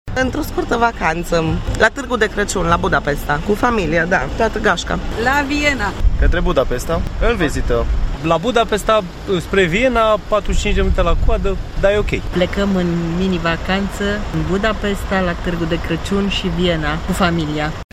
voxuri-Viena-Budapesta.mp3